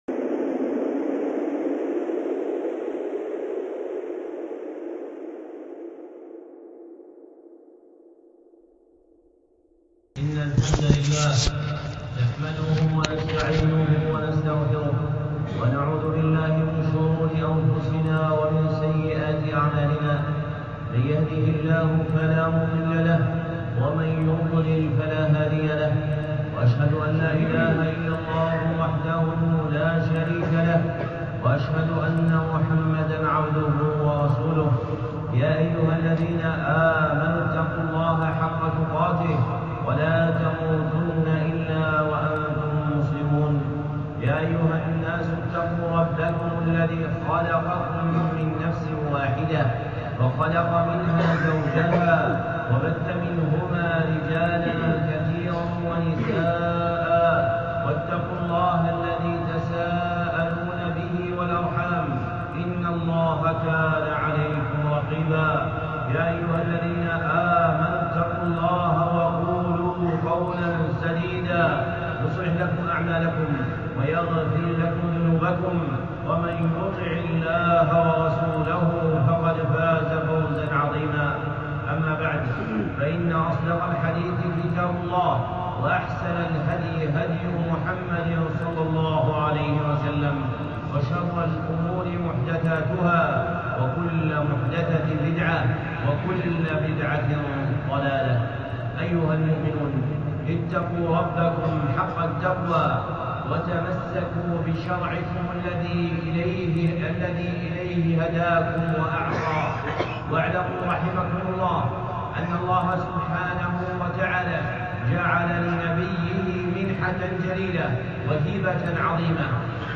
خطبة (حديث أم السنة) الشيخ صالح العصيمي
الخطب المنبرية للشيخ صالح بن حمد العصيمي